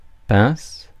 Ääntäminen
Tuntematon aksentti: IPA: /pɛ̃s/